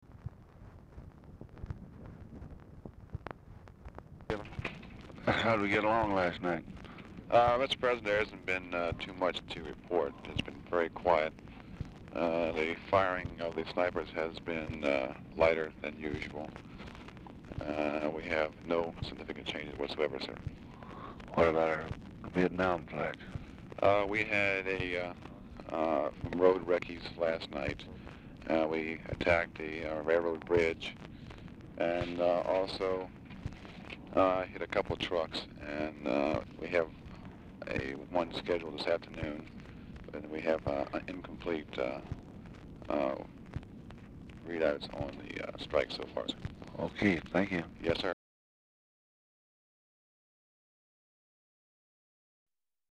Telephone conversation # 7622, sound recording, LBJ and WH SITUATION ROOM, 5/11/1965, 5:52AM | Discover LBJ
Format Dictation belt
Specific Item Type Telephone conversation Subject Defense Latin America Vietnam